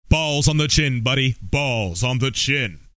Tags: comedy announcer funny spoof crude radio